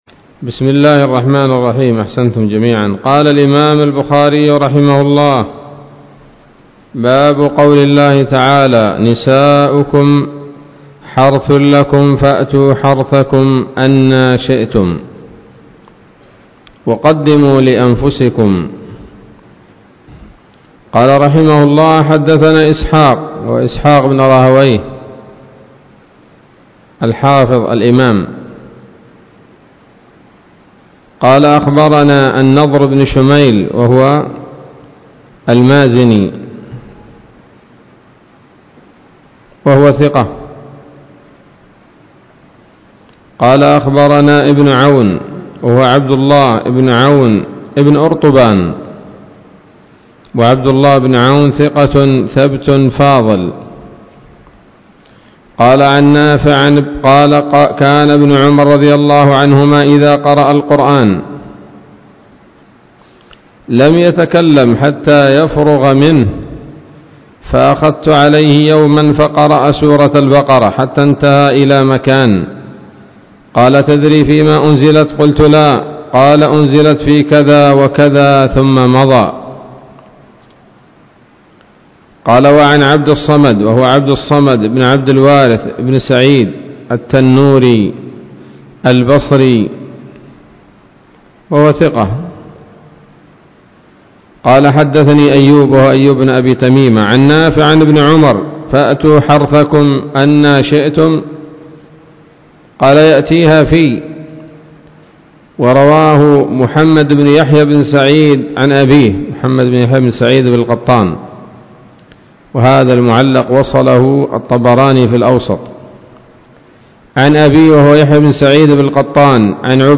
الدرس الثاني والثلاثون من كتاب التفسير من صحيح الإمام البخاري